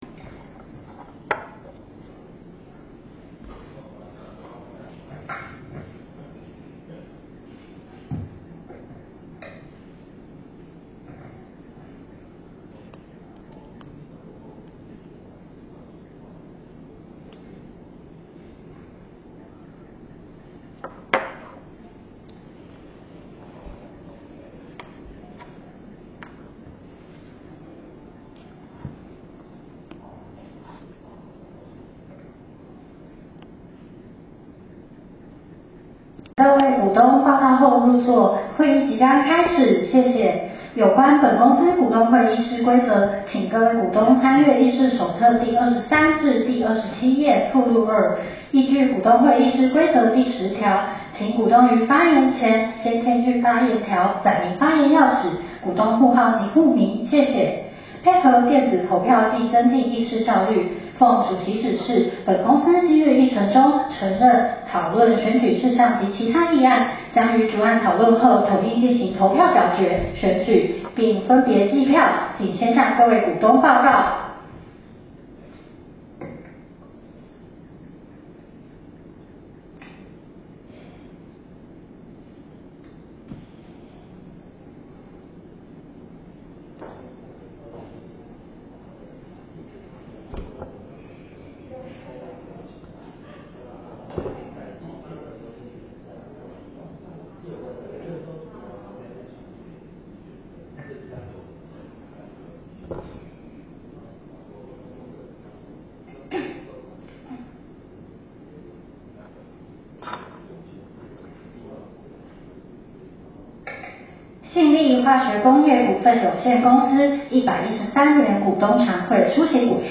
召開地點：台南市安平區永華路二段248號18樓之7(本公司永華會議廳)
113年信立股東會錄音檔.m4a